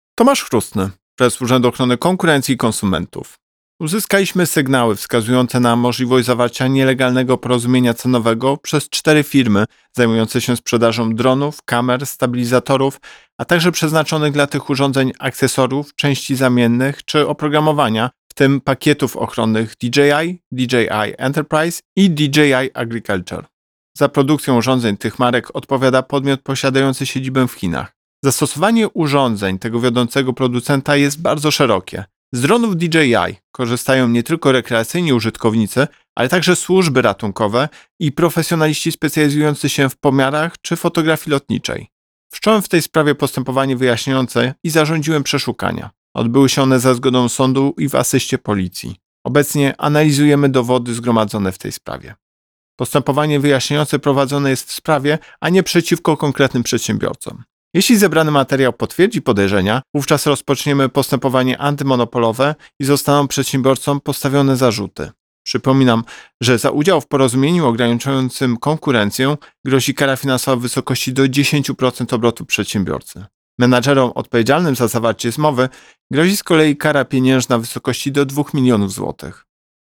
Wypowiedź Prezesa UOKiK Tomasza Chróstnego z 7 października 2024 r..mp3